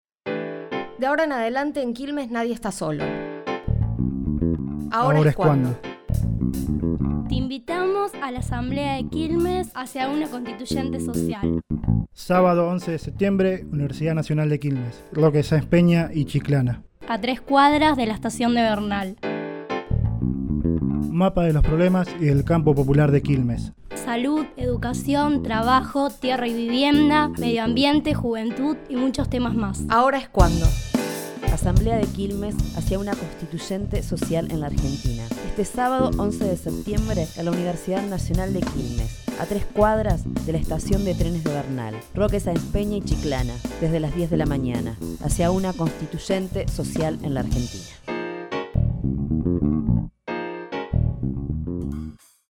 Spot de difusión de la Asamblea de Quilmes